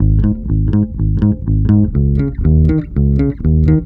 INC BASSE.wav